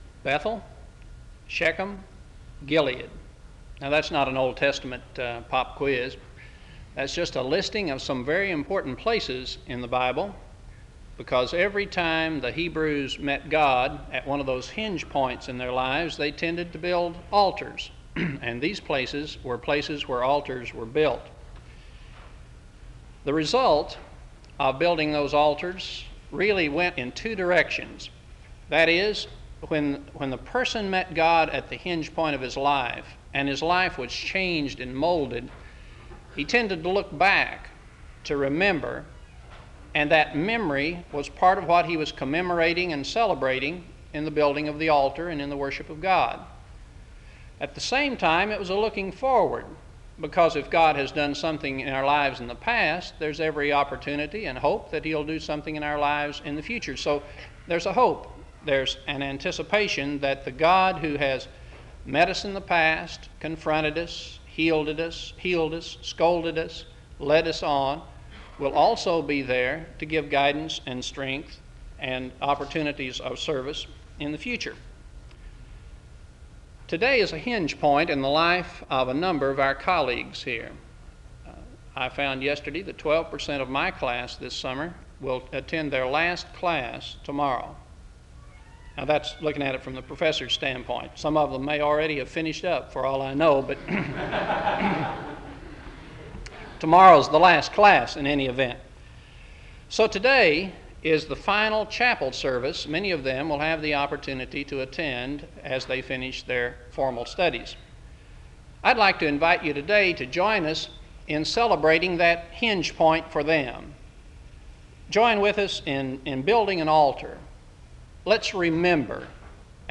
SEBTS Chapel
SEBTS Chapel and Special Event Recordings